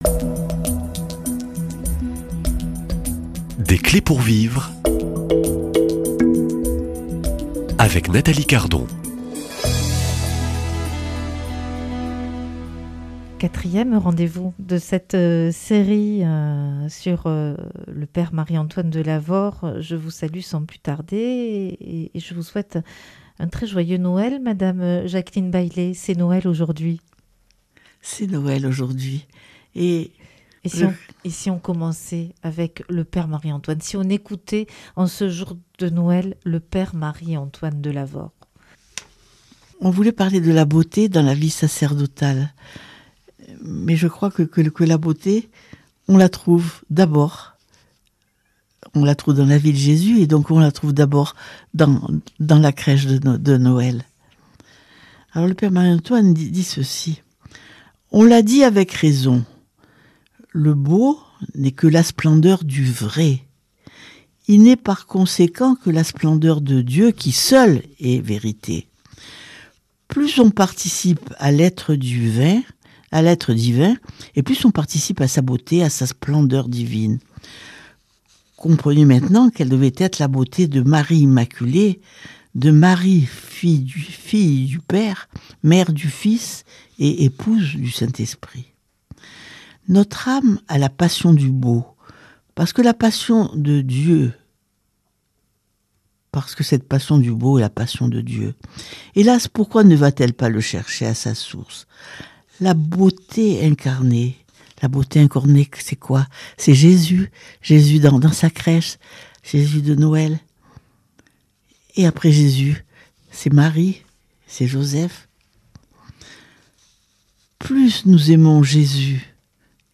Dans cet épisode, nous découvrons comment le Père Marie-Antoine voyait dans la beauté la « splendeur de Dieu ». Pauvre parmi les pauvres, mais exigeant pour la liturgie, les églises et l'art sacré, il considérait que tout ce qui est tourné vers Dieu doit être beau. À travers son regard théologique et son œuvre missionnaire, cet entretien montre comment la beauté élève, convertit et conduit à la vérité.